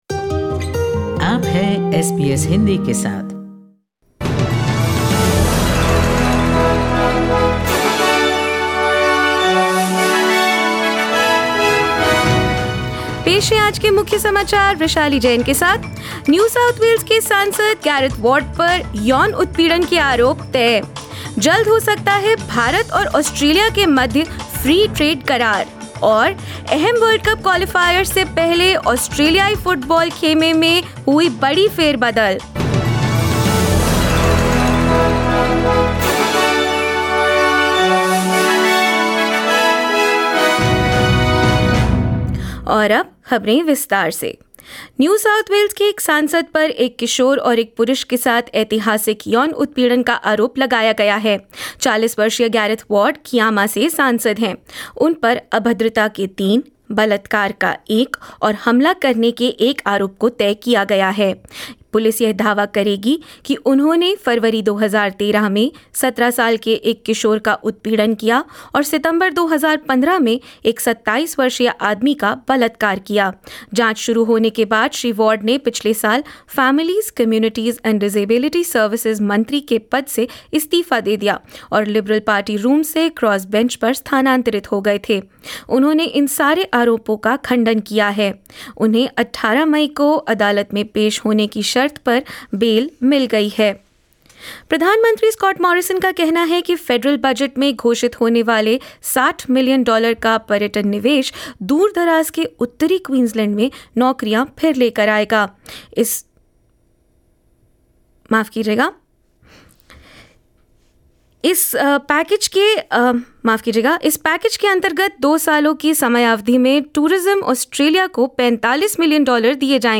SBS Hindi News 22 March 2022: Australia and India may soon ink the free trade agreement